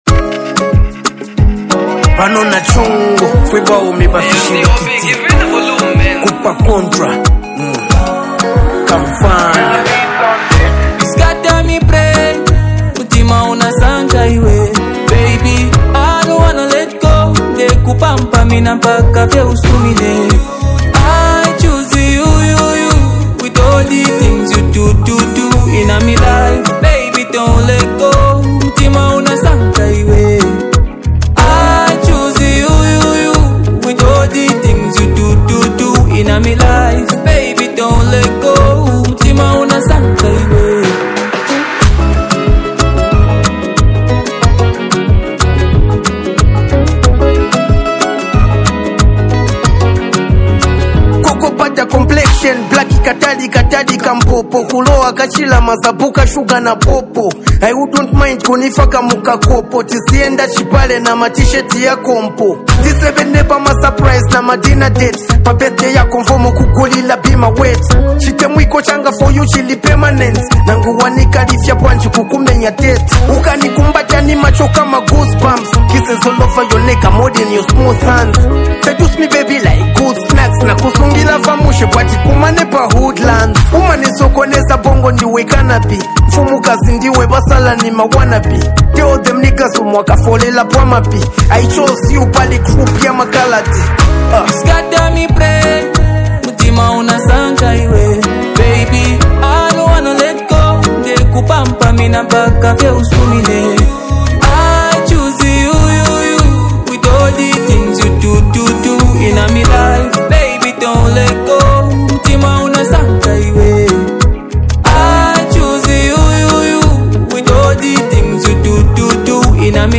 a heartfelt song